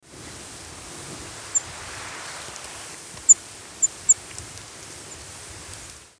Pine Warbler diurnal flight calls
Diurnal calling sequences: